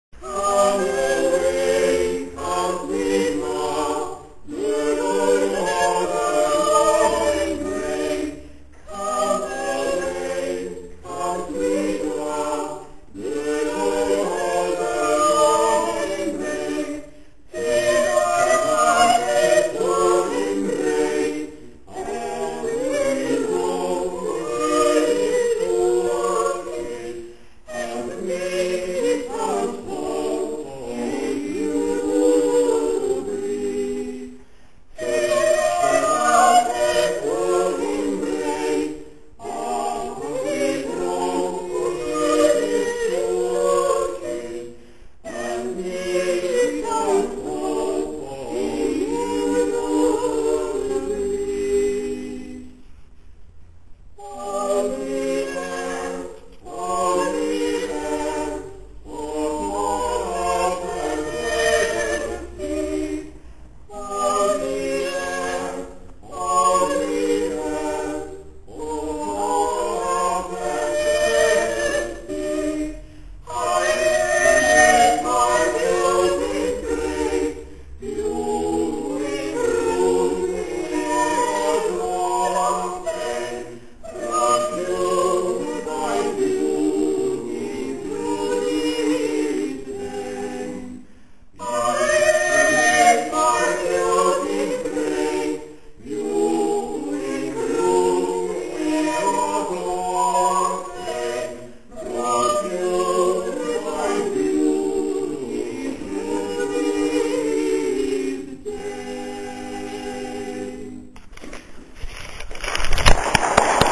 25. komorní koncert na radnici v Modřicích
vokální soubor Sol et Sedes
Ukázkové amatérské nahrávky WMA: